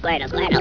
squirtlesound.wav